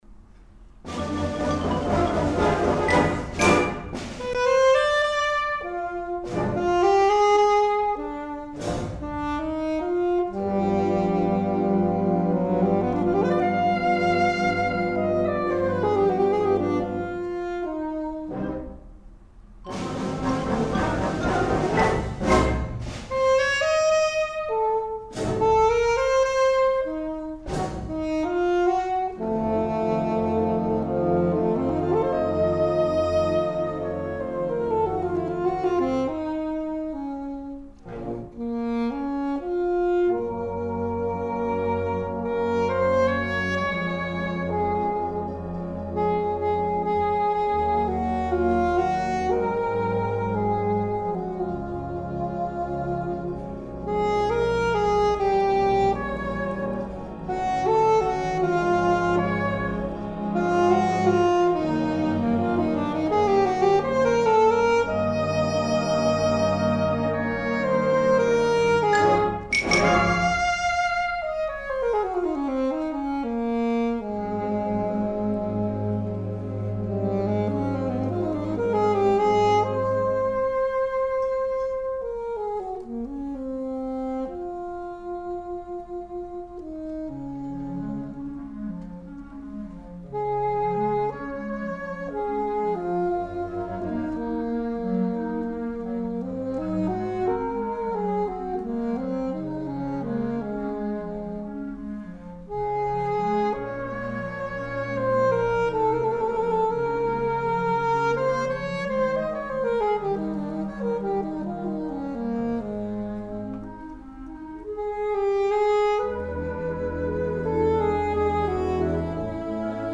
Voicing: Alto Saxophone w/ Band